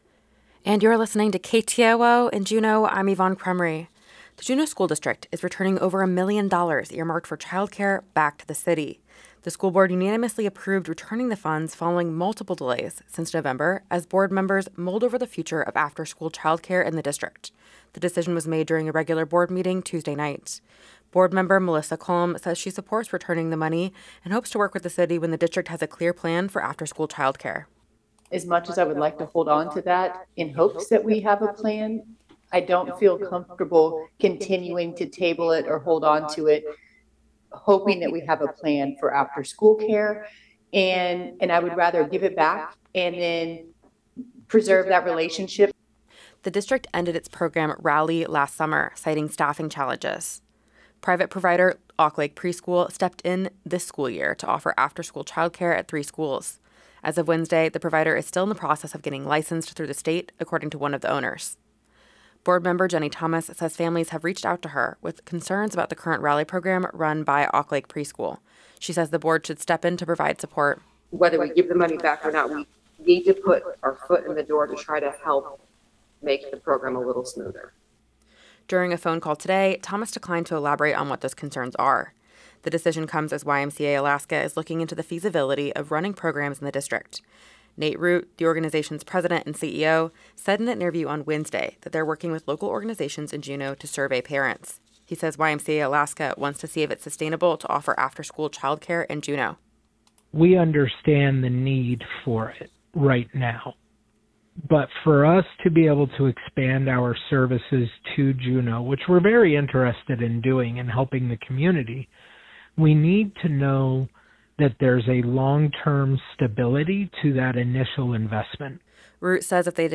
The latest local, state, and regional news is compiled from reports from the KTOO newsroom, CoastAlaska stations, wire services, the Alaska Public Radio Network, and the Canadian Broadcasting Corporation and can be heard as often as six times each morning during Morning Edition, and twice in the afternoon during The World and All Things Considered.
Newscast – Thursday, Feb. 2, 2025